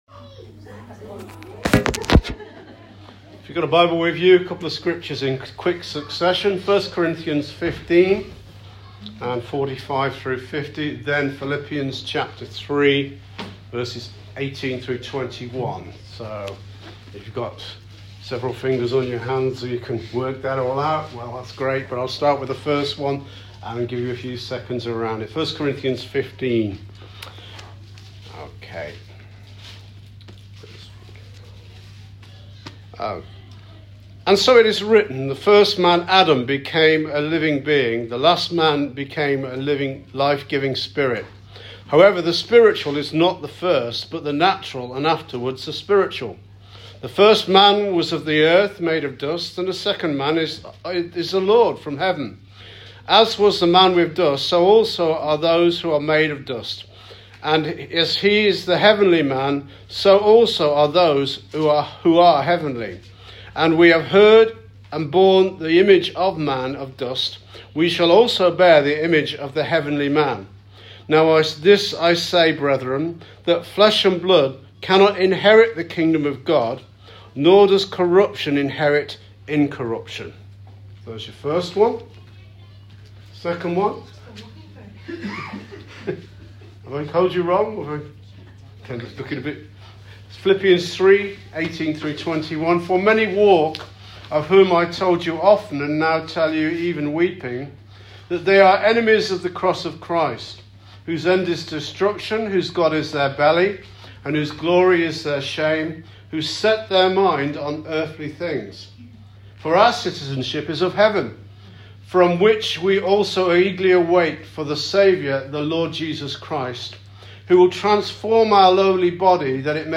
SERMON "WHO IS ON THE LORDS SIDE”